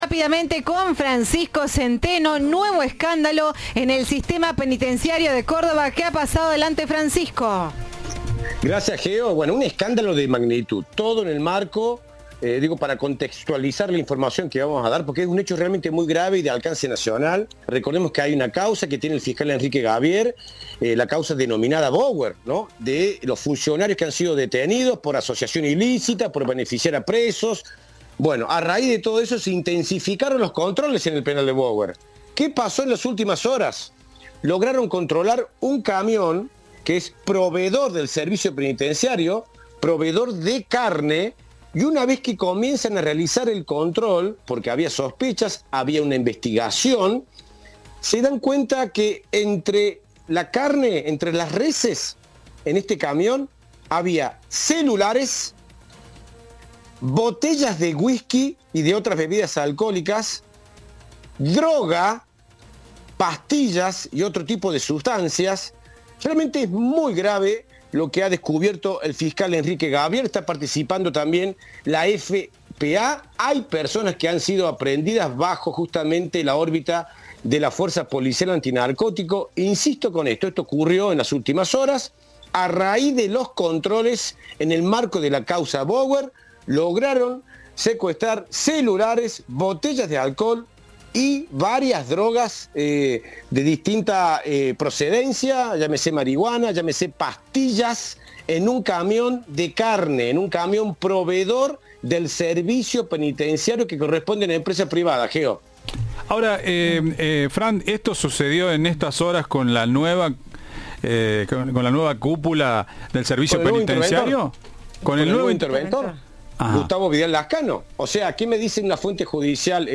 En diálogo con Cadena 3, Vidal Lascano indicó: “Desde que nos hicimos cargo de la intervención del SPP hemos dispuesto profundizar las requisas que habitualmente se realizan; se hacen de manera aleatoria a todo el que ingresa al penal”.
Informe